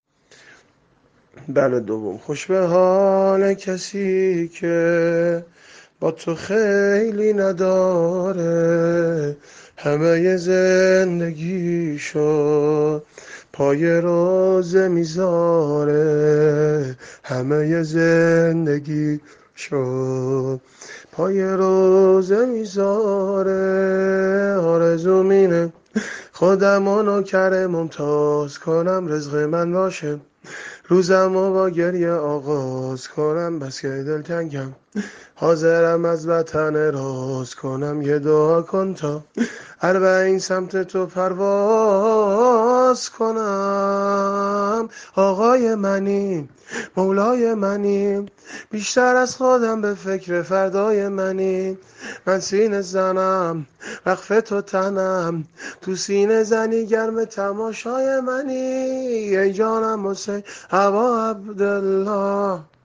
متن شعر و سبک شور اباعبدالله حسین(ع) -(خوش به حال کسی که، نفسش به تو بسته)